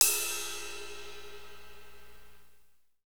Index of /90_sSampleCDs/Northstar - Drumscapes Roland/DRM_AC Lite Jazz/CYM_A_C Cymbalsx